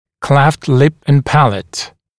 [kleft lɪp ən ‘pælət][клэфт лип эн ‘пэлэт]расщелина губы и твердого нёба